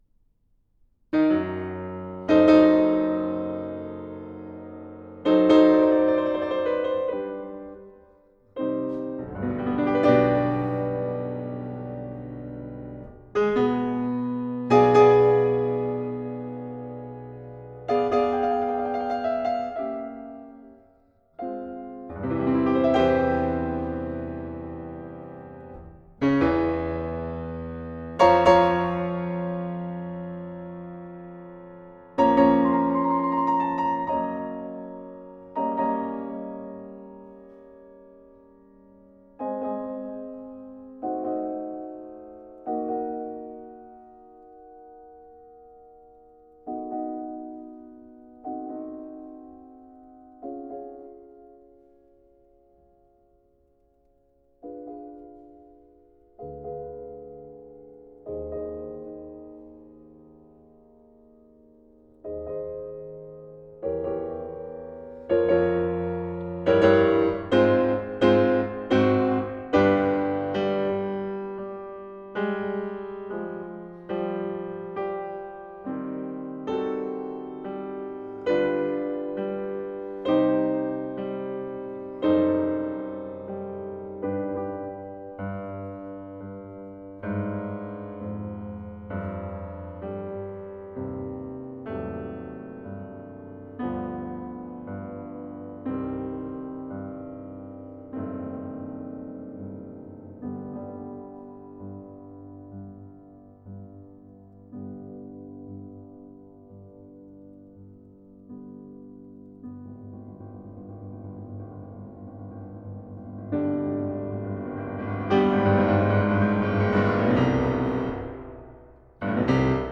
Classical
Sonata no. 32 in C minor, first movement, performed by Alfred Brendel. This is Beethoven's last piano sonata.